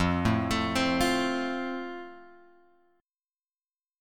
F chord {1 0 3 2 1 x} chord